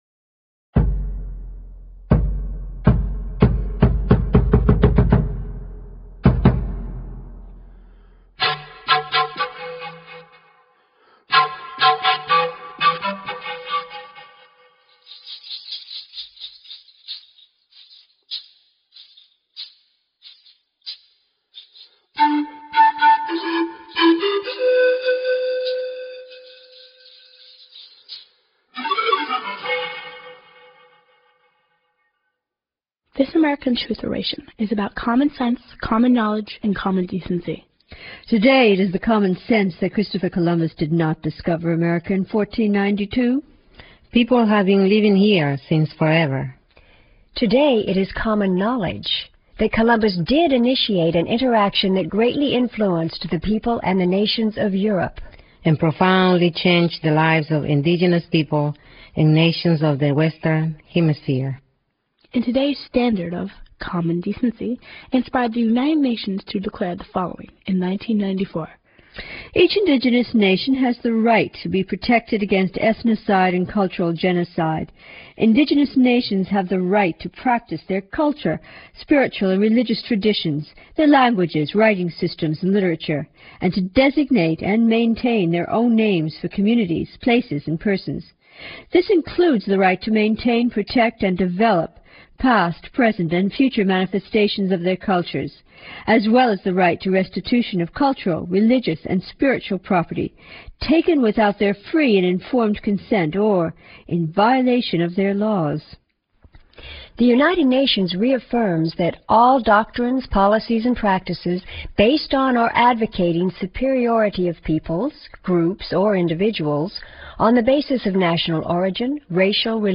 All of these documented materials are dramatically presented with historically relevant music, and by four Speakers: